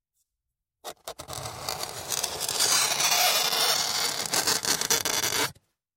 Звук скрипа ногтя по стеклу